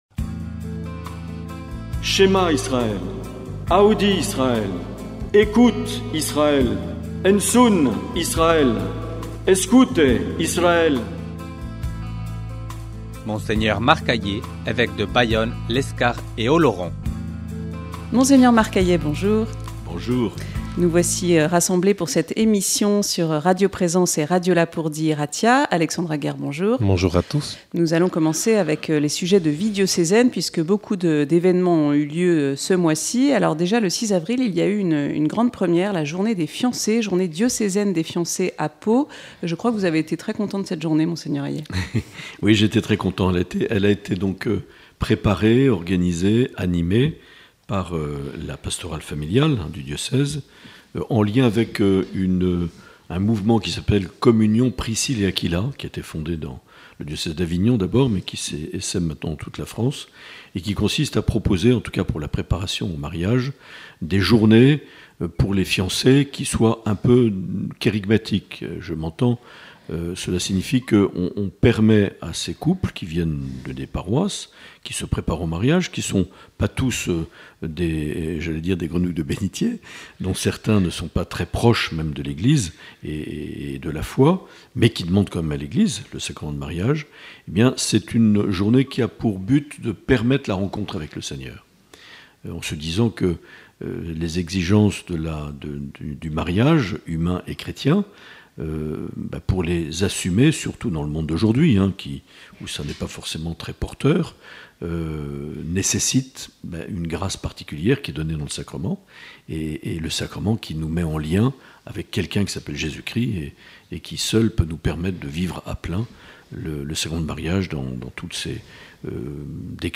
Dans cet entretien enregistré le 7 mai 2024, Mgr Marc Aillet répond aux questions de Radio Lapurdi et de Radio Présence Lourdes Pyrénées.